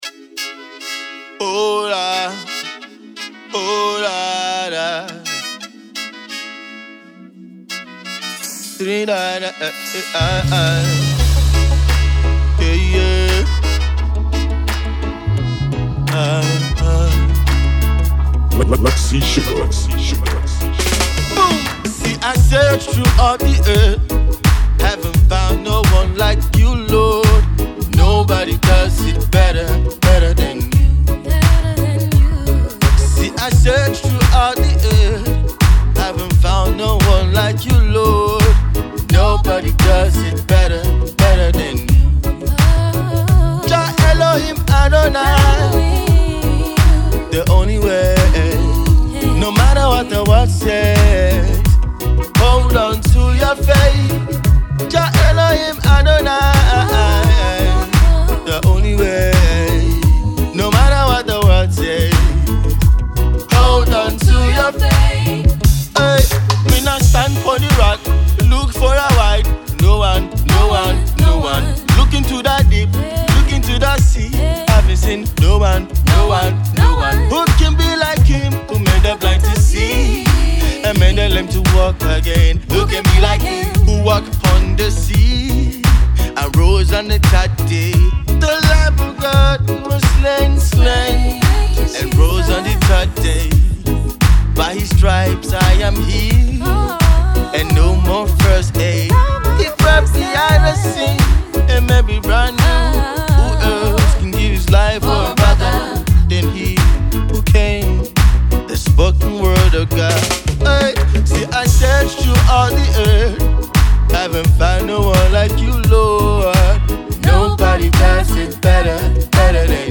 a gospel artist from Osun State